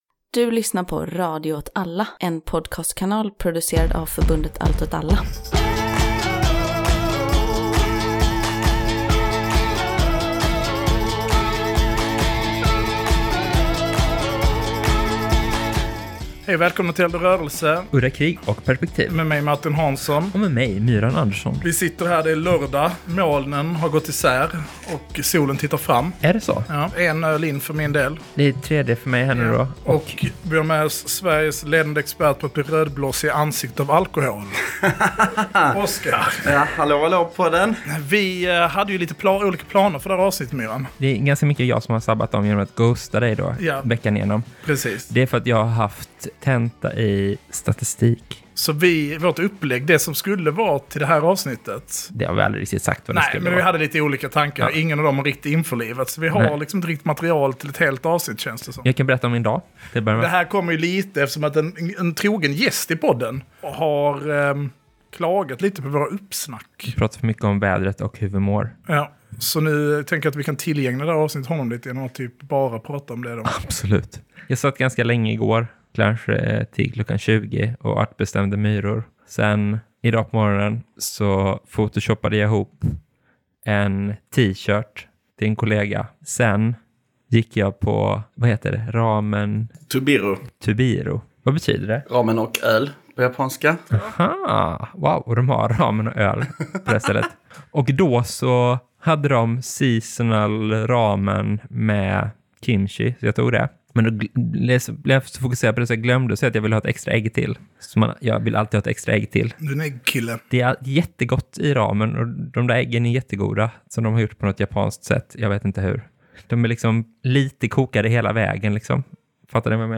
Men kycklingen blev god och det blev ett avsnitt. Justdet, vi har också med en gäst som är expert på att bli rödblossig i ansiktet under vissa förutsättningar.